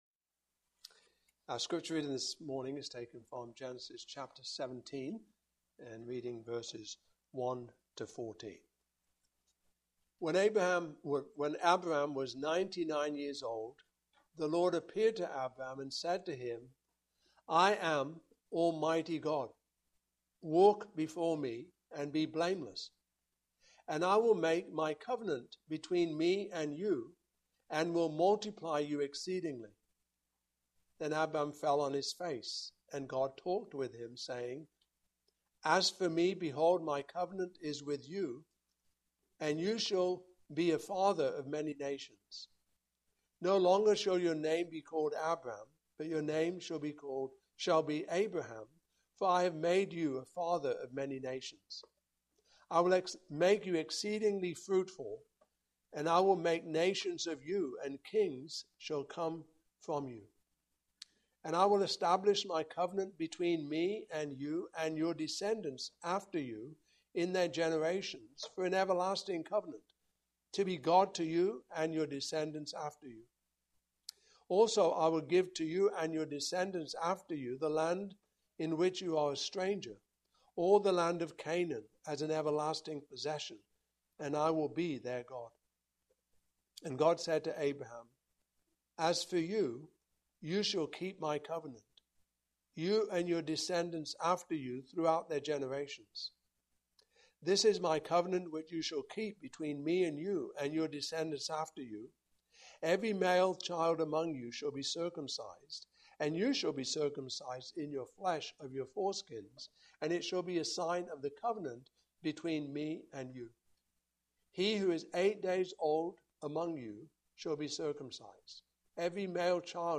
Passage: Genesis 17:1-14 Service Type: Morning Service